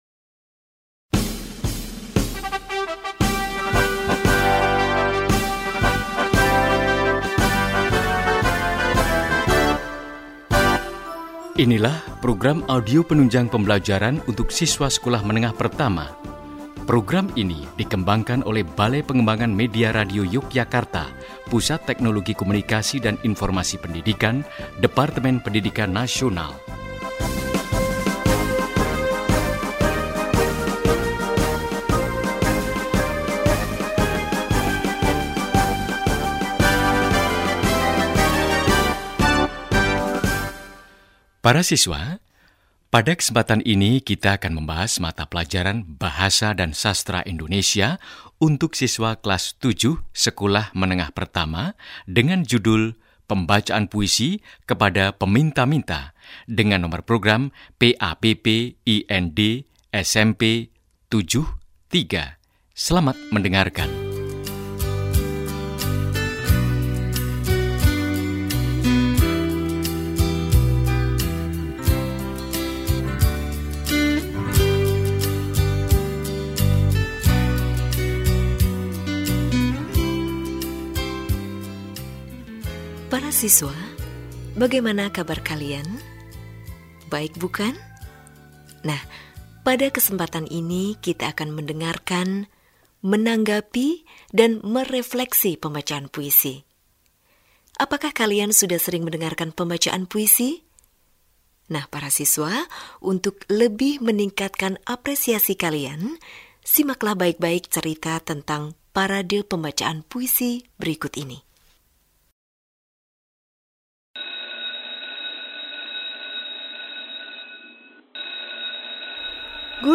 Pembacaan Puisi Kepada Peminta-minta
677_pembacaan_puisi_kepada_peminta-minta.mp3